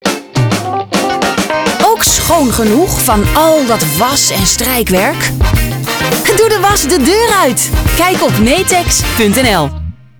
■ Radiocommercials uitgezonden op BNR Radio vanaf 13 juni 2019: